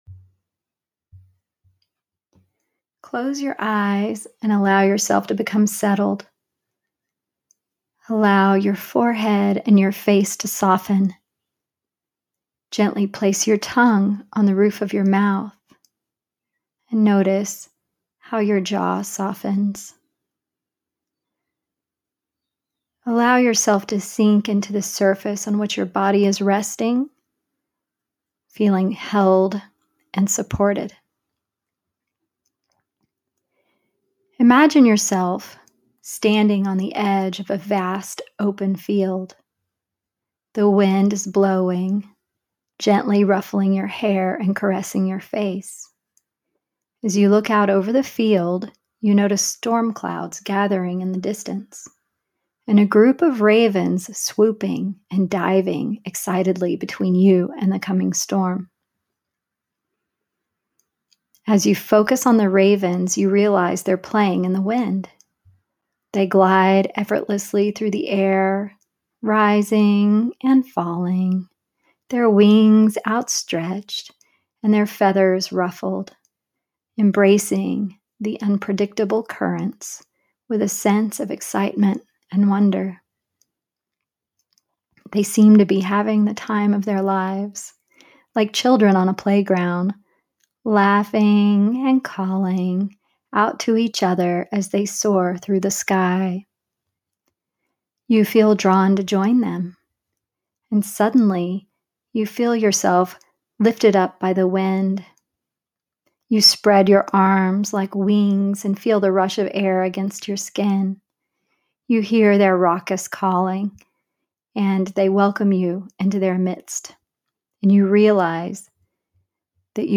Wind is the element of the East and Raven, who relishes playing in the edgy winds just before a storm rolls in, is an excellent guide to help us navigate these winds of change. I've recorded a brief guided visualization (4:35min audio), a message from Raven to help foster a mindset toward surfing the winds of change with levity, creativity and playful curiosity, rather than the fear and uncertainty, anxiety and restriction that can often accompany times of great transition.